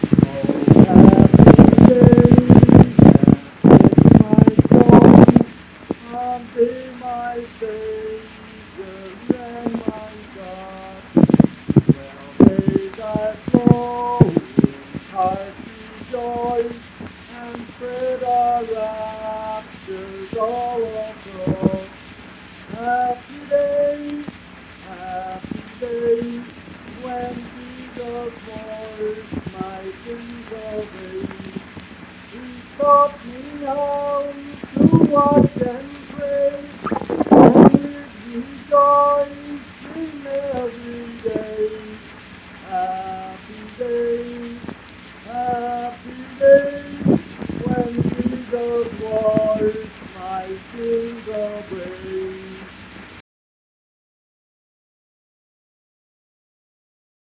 Unaccompanied vocal music performance
Hymns and Spiritual Music
Voice (sung)